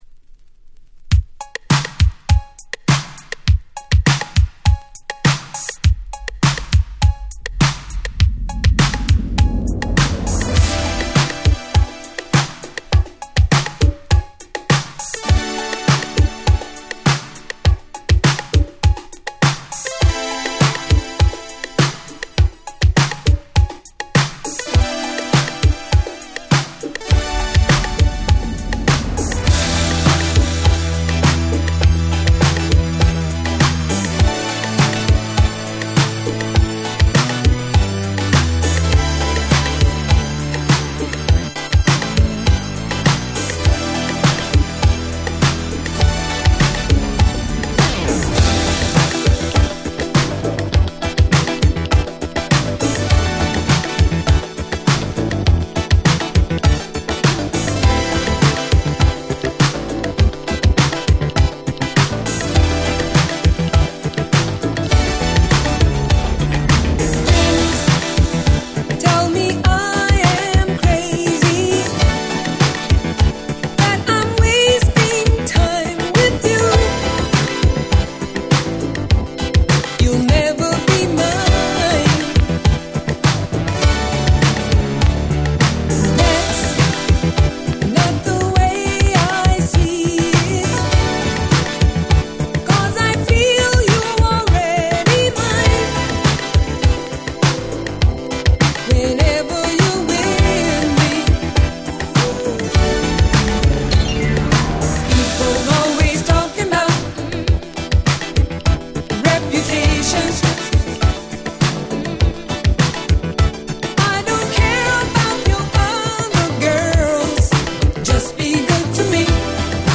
83' Super Disco Classics !!
Vocal Remix
80's Disco ディスコ ダンクラ ダンスクラシックス Dance Classics